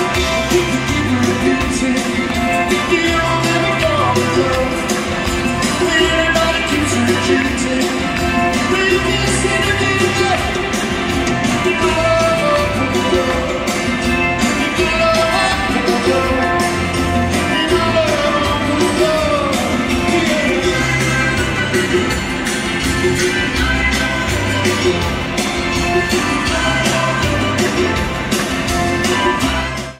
Comments: Poor audience recording.
Sound Samples (Compression Added):